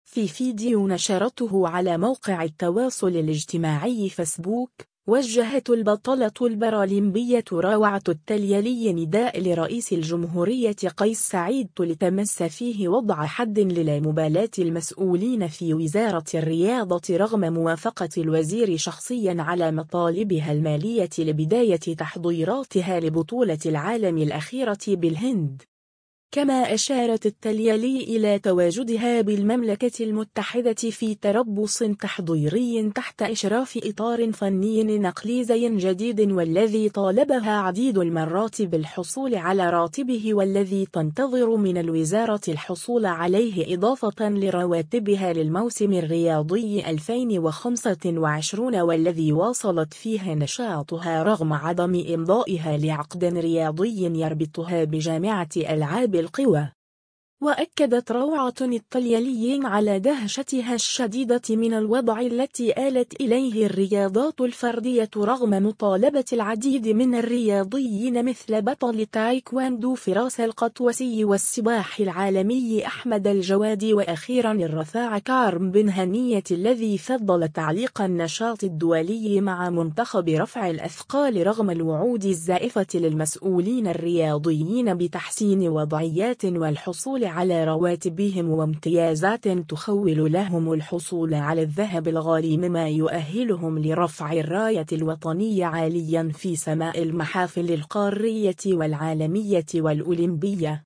البطلة البارالمبية روعة التليلي توجه نداء لرئيس الجمهورية قيس سعيد (فيديو)